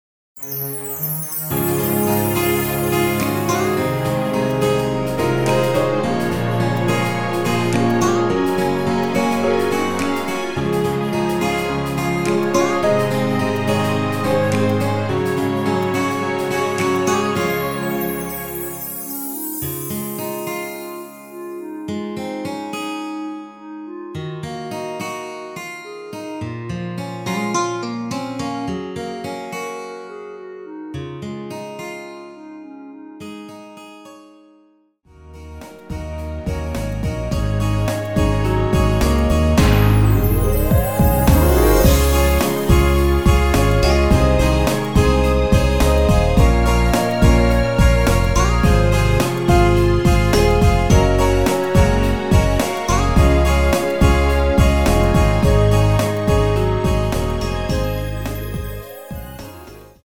Db
노래방에서 노래를 부르실때 노래 부분에 가이드 멜로디가 따라 나와서
앞부분30초, 뒷부분30초씩 편집해서 올려 드리고 있습니다.